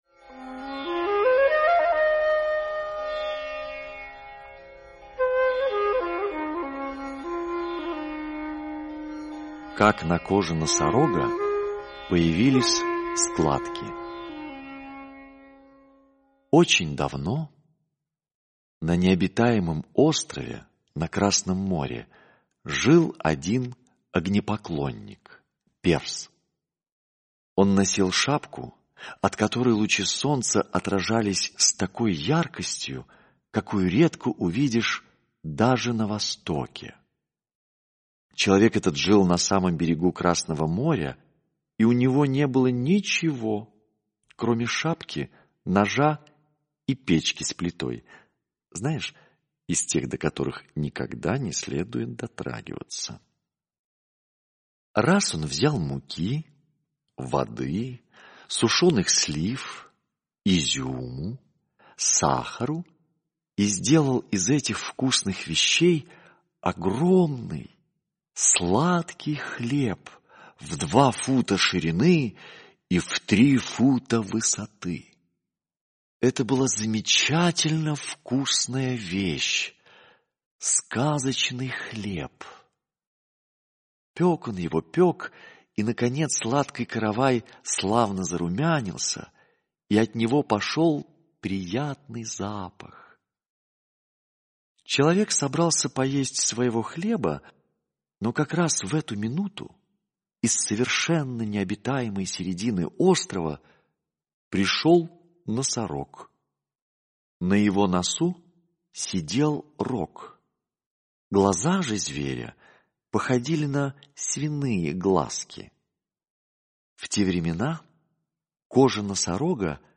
Аудиосказка «Как на коже носорога появились складки»